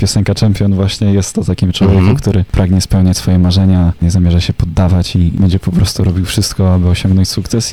O piosence opowiadał w audycji „Paczka Radomskich” na antenie Radia Radom, sam wokalista: